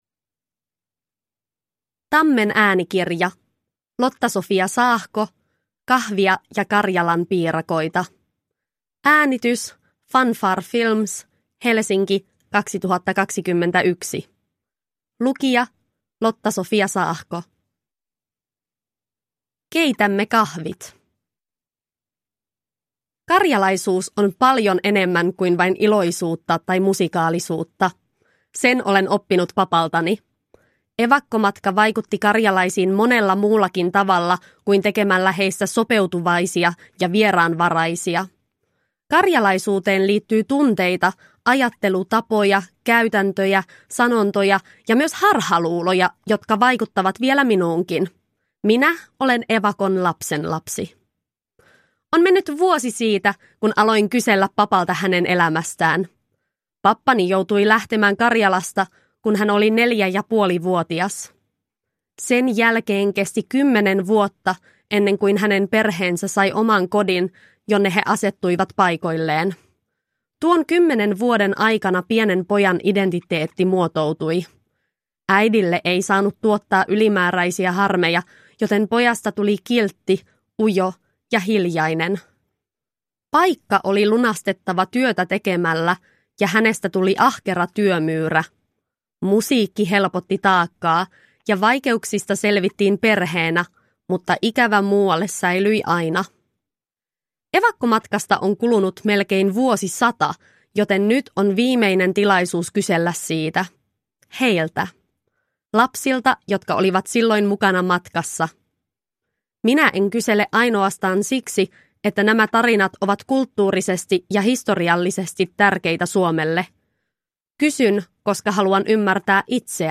Kahvia ja karjalanpiirakoita – Ljudbok – Laddas ner